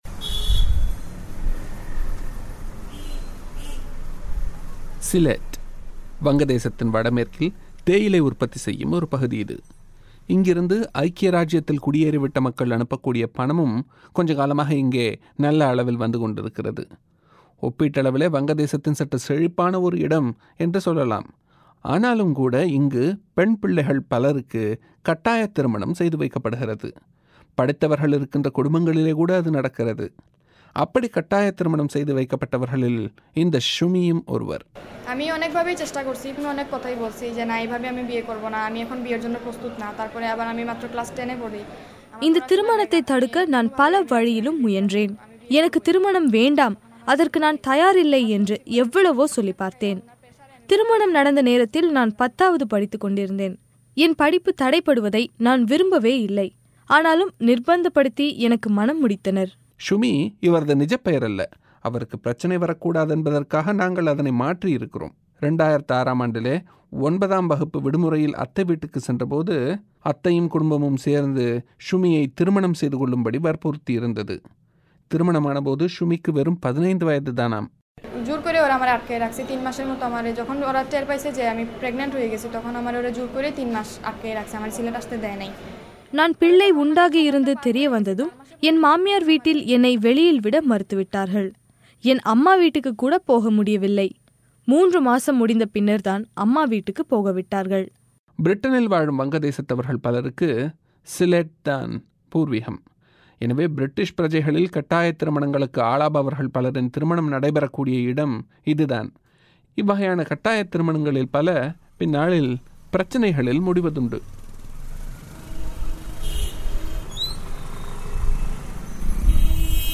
வங்கதேசத்தில் கட்டாயத் திருமணங்கள் - ஒரு பெட்டகம்